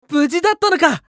青年ボイス～ホラー系ボイス～
【無事だったのか(喜)】